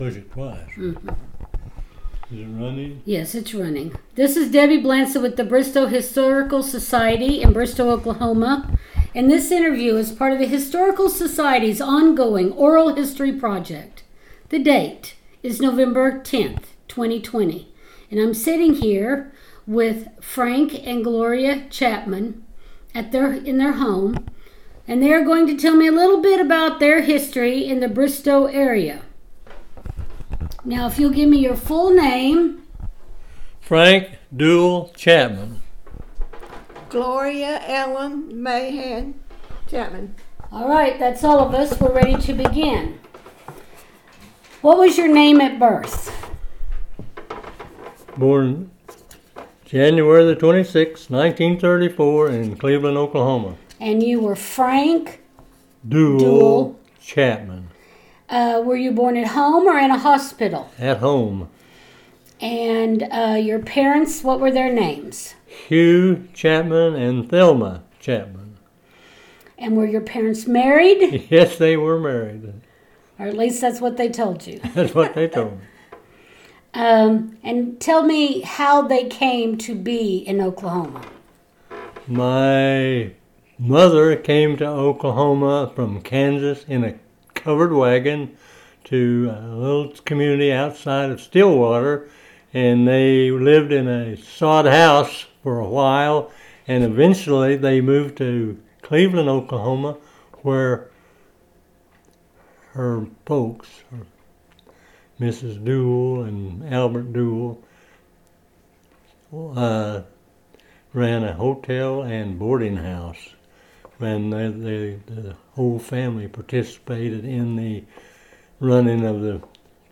Oral History Archive